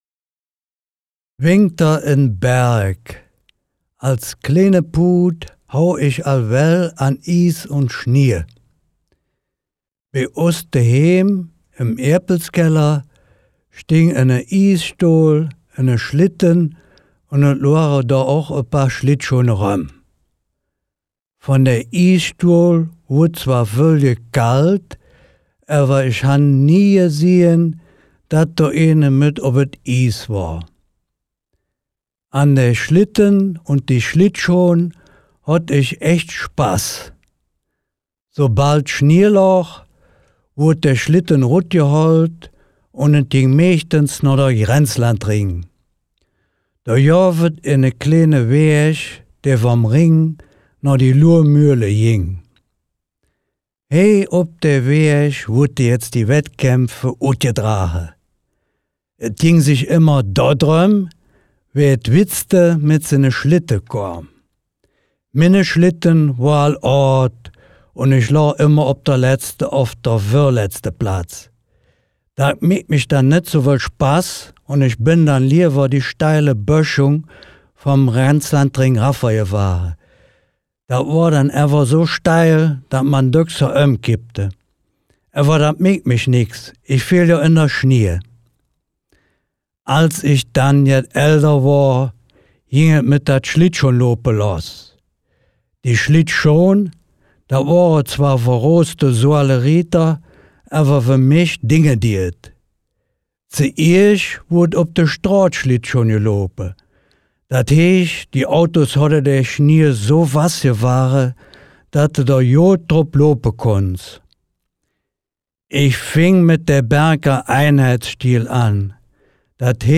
Text Mundart